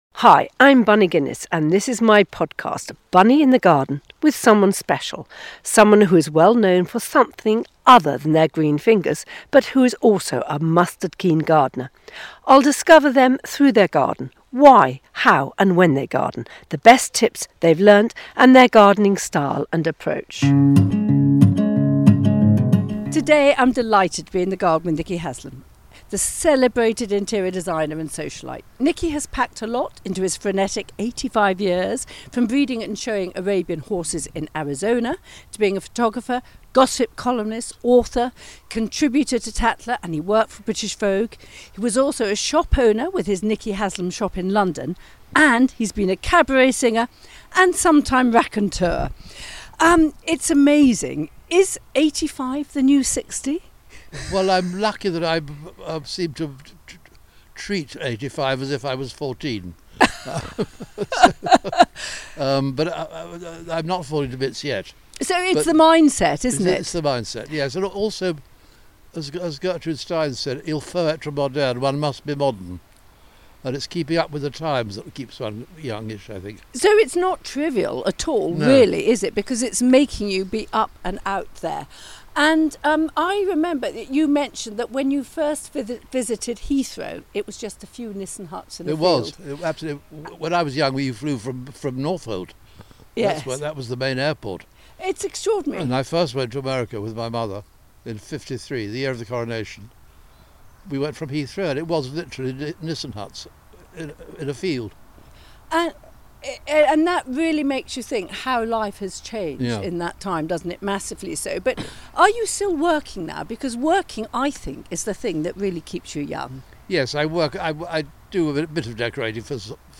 In this episode Bunny Guinness is chatting to Nicky Haslam, the celebrated interior designer, in his garden.